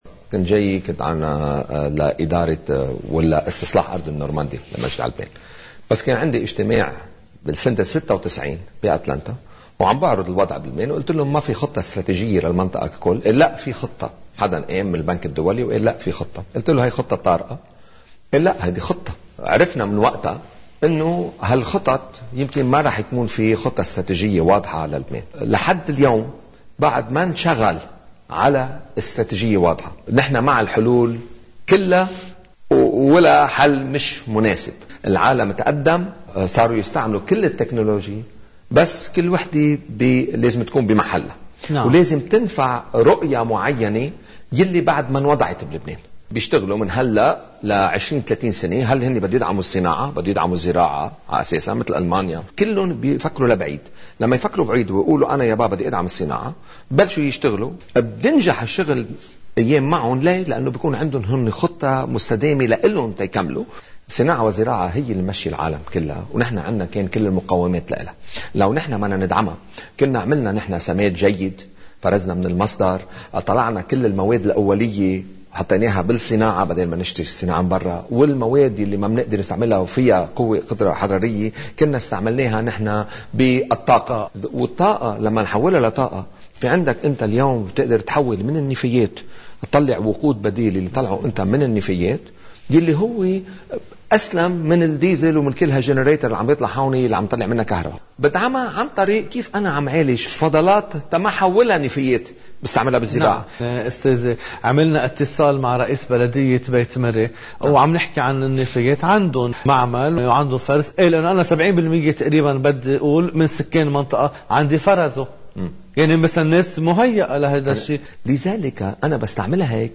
حديث